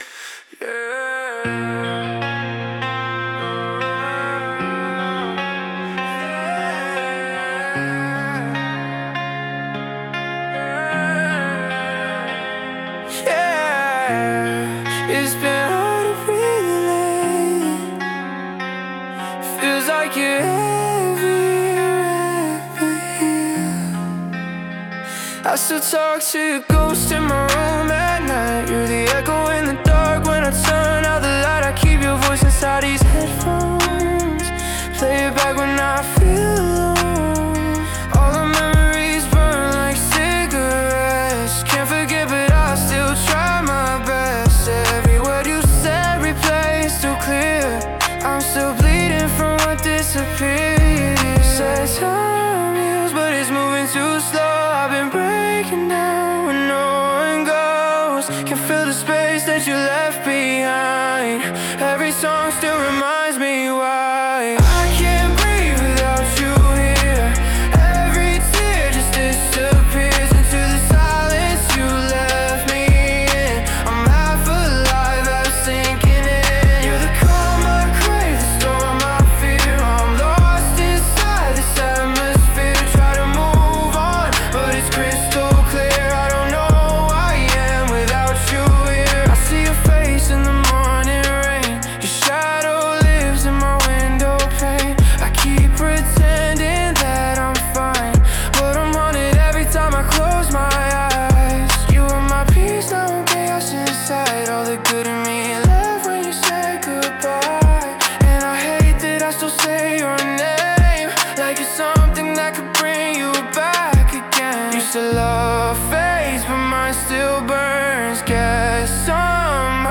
Genre: Emo Trap Mood: Suffocating